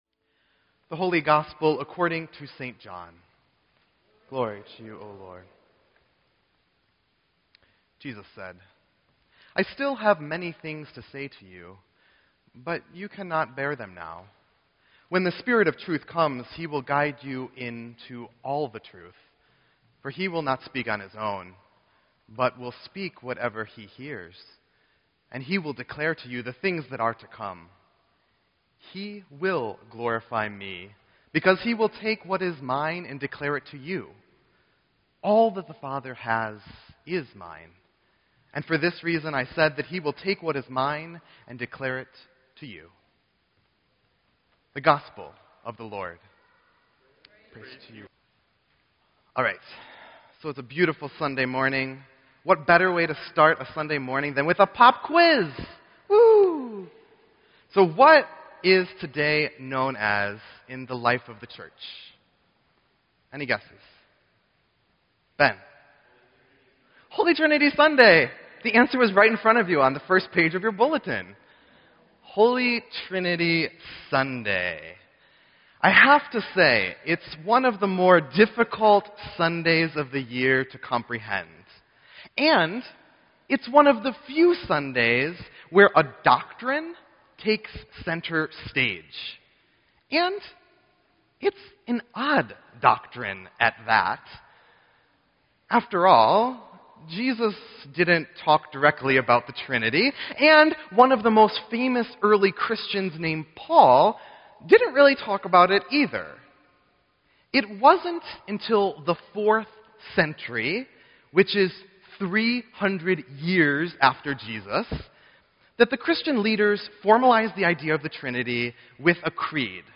Sermon_5_22_16.mp3